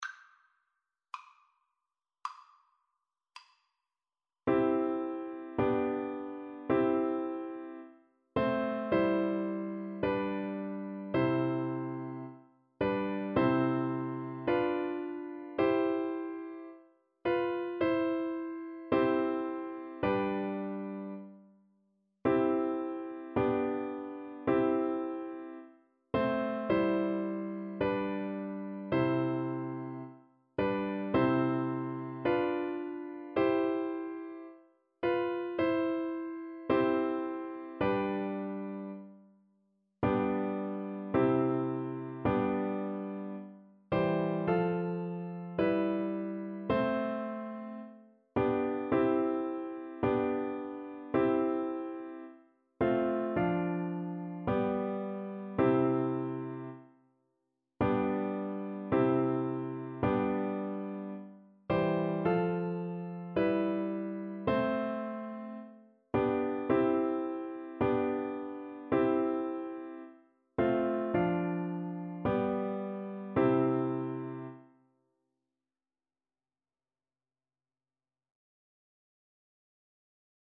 4/4 (View more 4/4 Music)
C major (Sounding Pitch) (View more C major Music for Flute )
= 54 Slow
Classical (View more Classical Flute Music)